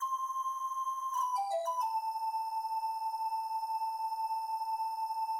blown bottle